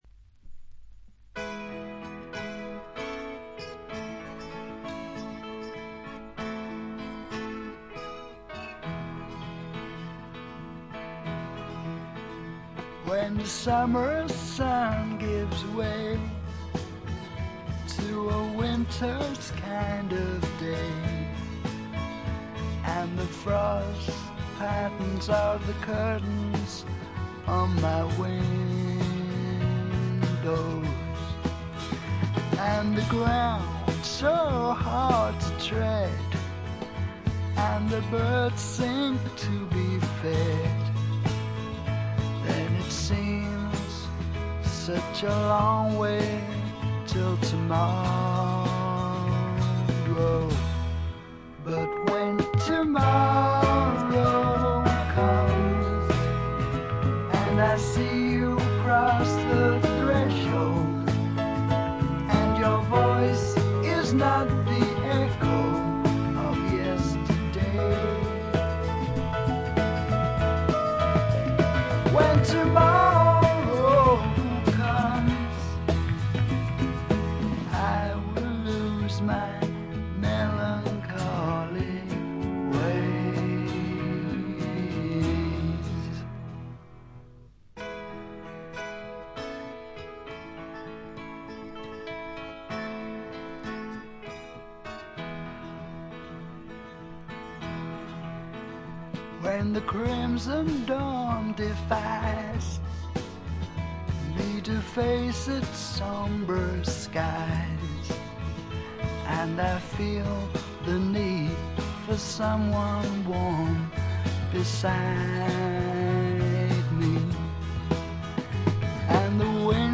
わずかなノイズ感のみ。
典型的かつ最良の霧の英国、哀愁の英国スワンプ路線の音作りで、端的に言って「アンドウェラしまくり」です。
搾り出すような激渋のヴォーカルがスワンプ・サウンドにばっちりはまってたまりません。
試聴曲は現品からの取り込み音源です。